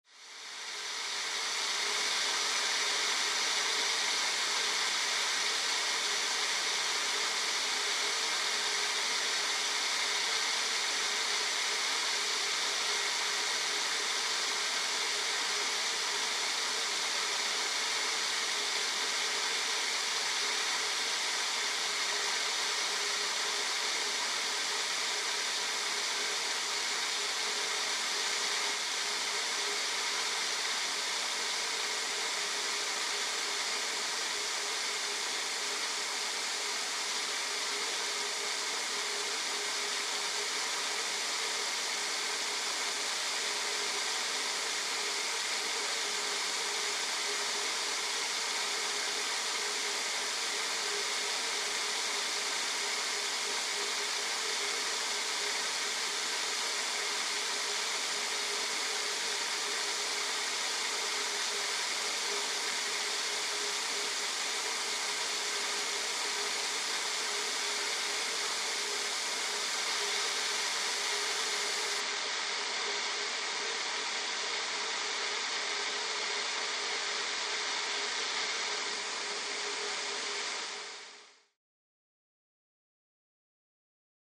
Steady Air Hiss